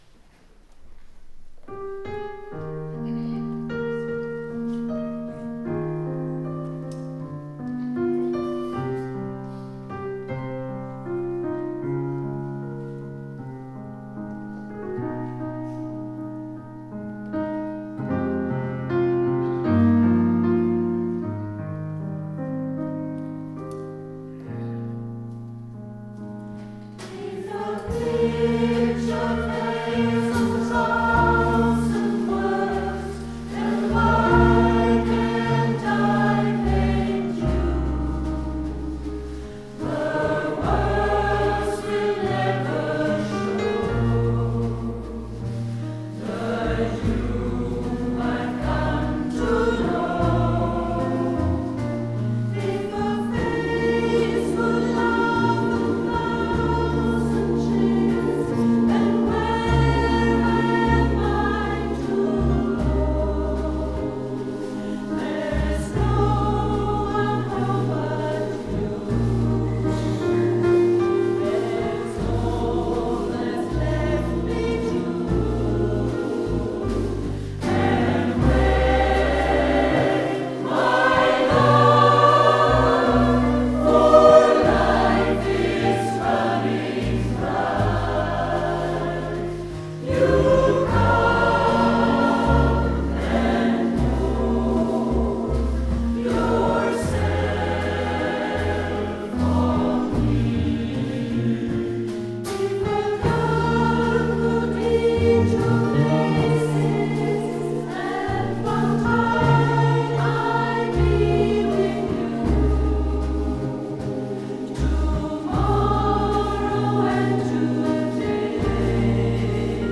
DaCapo - Konsert i �sterj�rns kyrka - 2017-06-11 Lets all get together Uti v�r hage S� l�nge skutan kan G� If